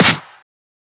edm-clap-50.wav